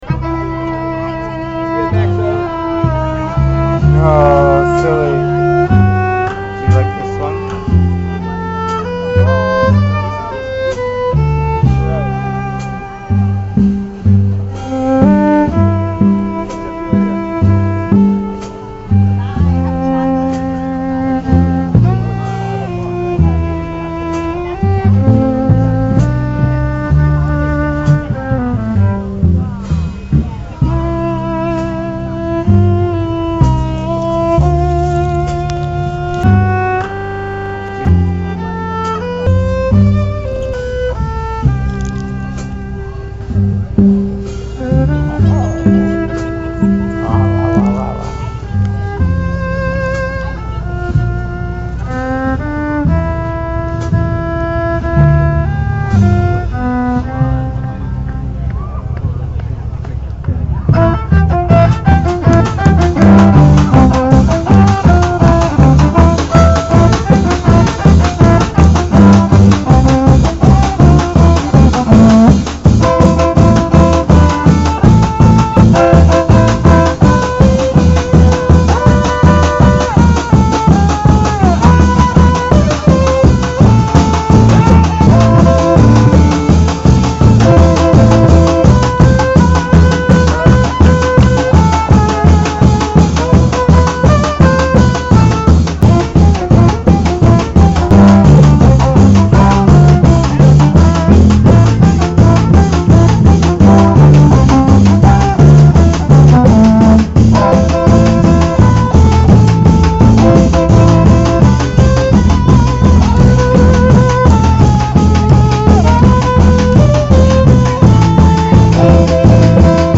Straight from Santa Monica farmer's market the morning of "Erev Christmas" – an unexpected medley
violin